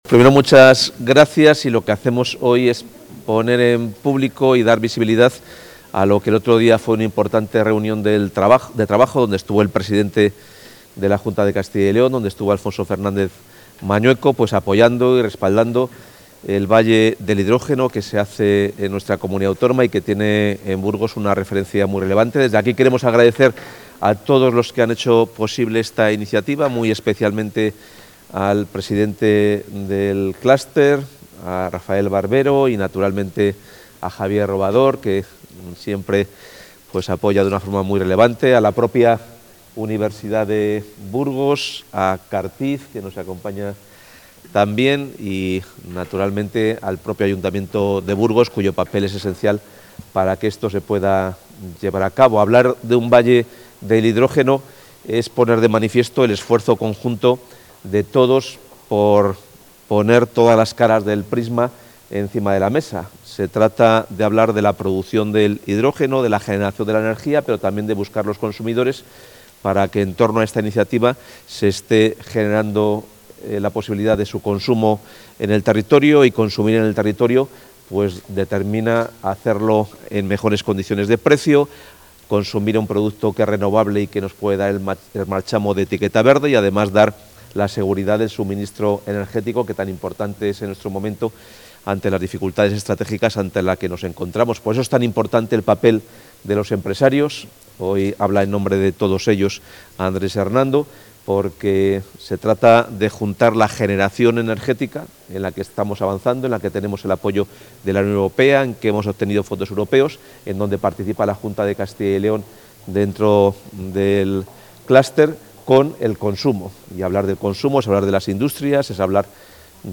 El consejero de Economía y Hacienda participa en el lanzamiento del Valle del Hidrógeno de Castilla y León
Intervención del consejero.